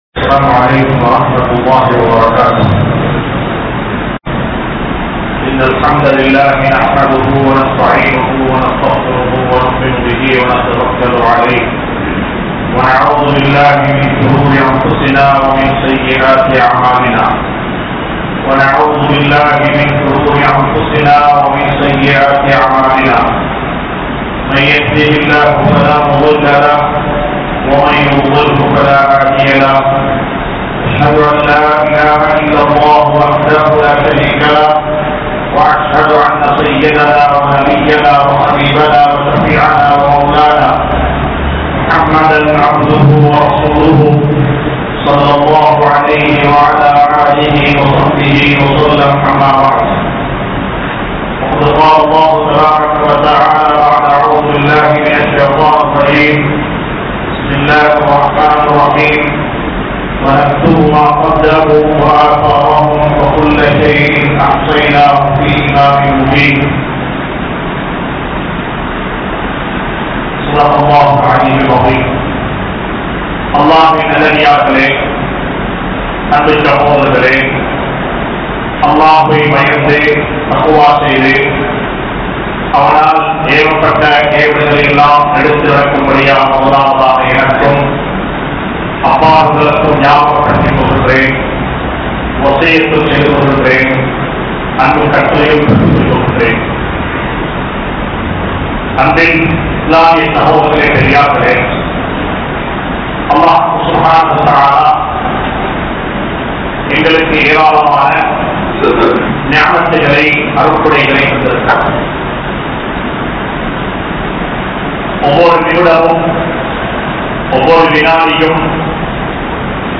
Media | Audio Bayans | All Ceylon Muslim Youth Community | Addalaichenai
Colombo 06,Kirulapana, Thaqwa Jumua Masjith